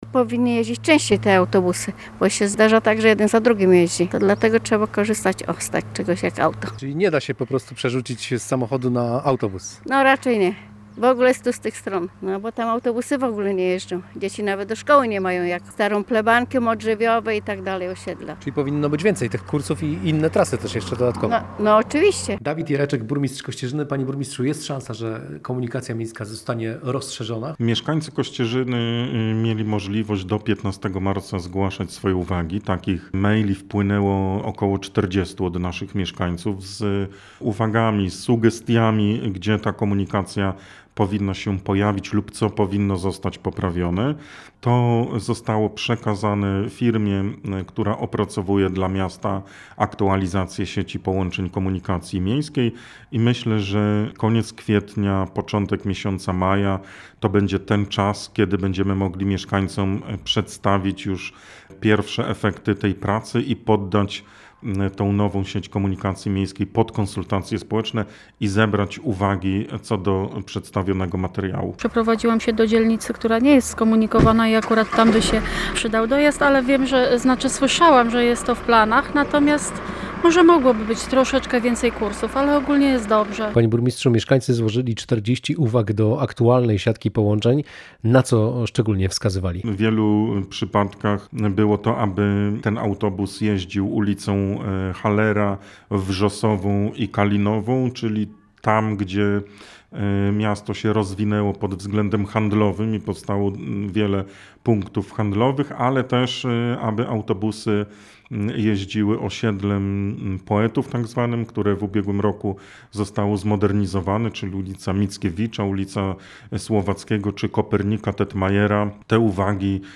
Autobusy nie dojeżdżają do wszystkich rejonów miasta i jeżdżą za rzadko – to główne uwagi zgłaszane przez mieszkańców Kościerzyny. Jak wyjaśnia burmistrz miasta Dawid Jereczek, teraz opracowana zostanie nowa siatka połączeń.